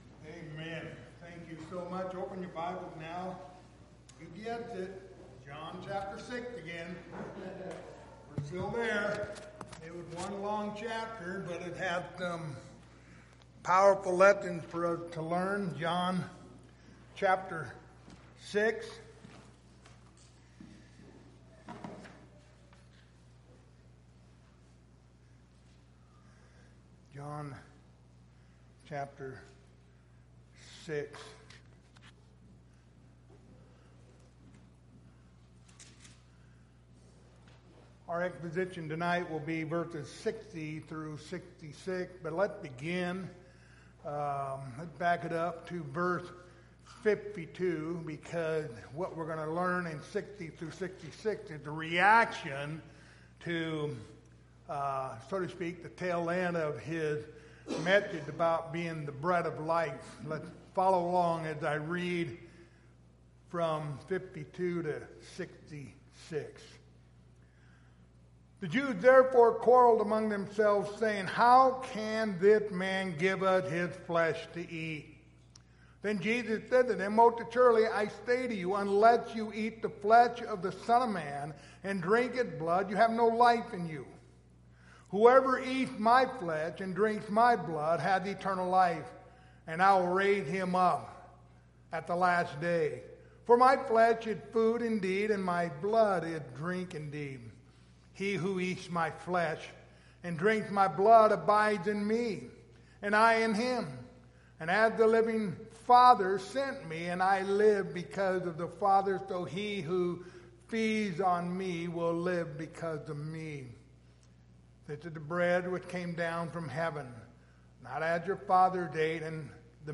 Passage: John 6:60-66 Service Type: Wednesday Evening